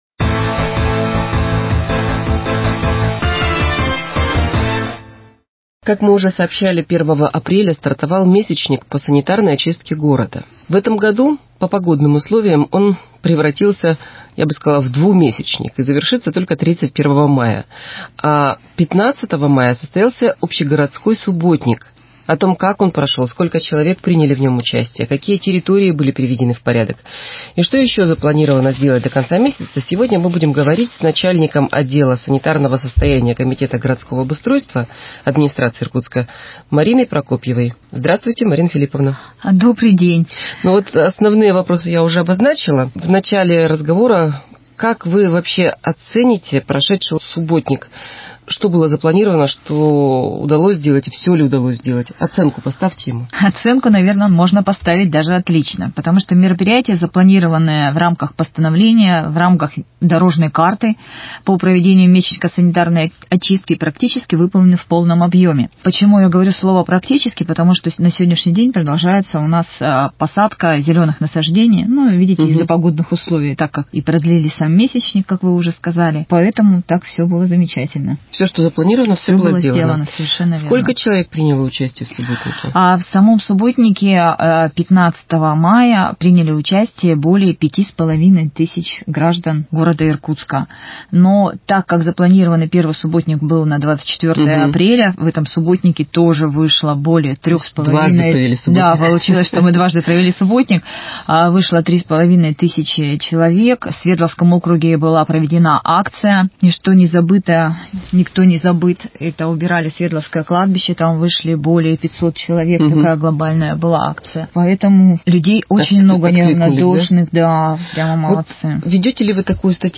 Актуальное интервью: Месячник по уборке Иркутска 19.05.2021